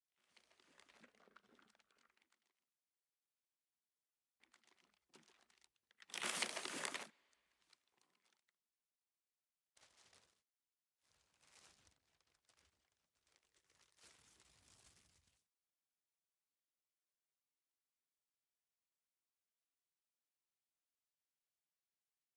04_书店内_奶奶写字.ogg